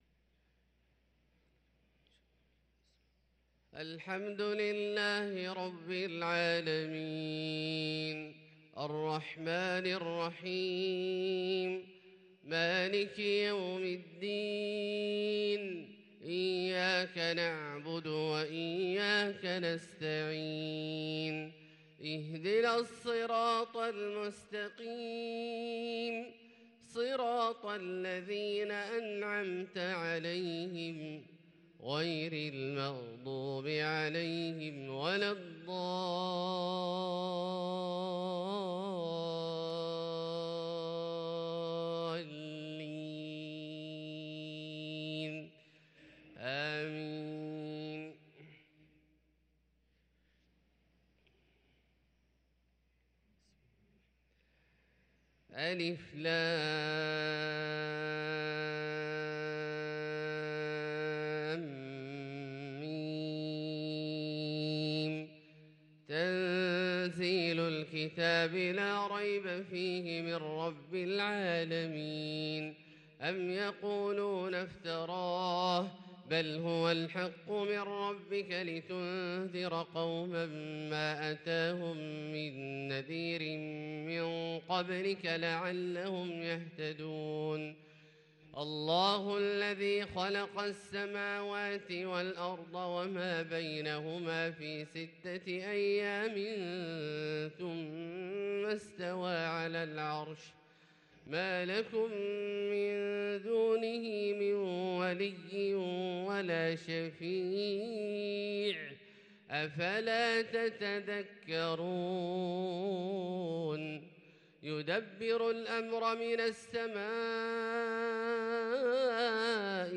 صلاة الفجر للقارئ عبدالله الجهني 22 جمادي الأول 1444 هـ
تِلَاوَات الْحَرَمَيْن .